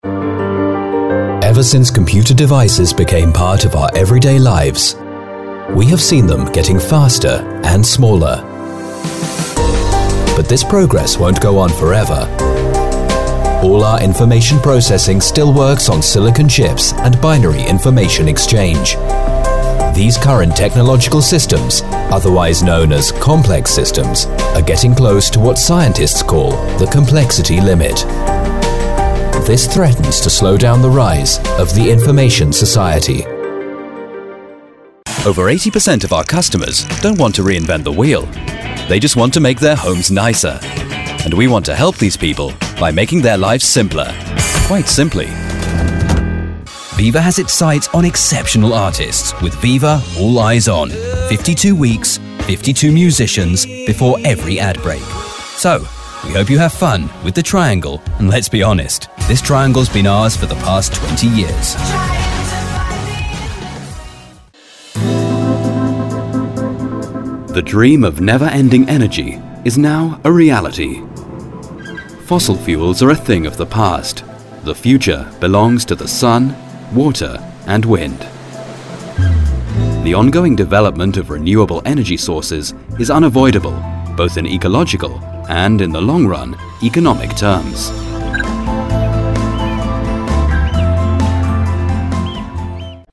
Meine Stimme kann warm, beruhigend, bestimmt oder energetisch wirken, und ich spreche ein klassisches, akzentfreies britisch Englisch.
Ein erfahrener englischer Sprecher mit einer warmen, dynamischen Stimme!
Sprechprobe: Industrie (Muttersprache):
An experienced VO talent, with a warm and dynamic voice.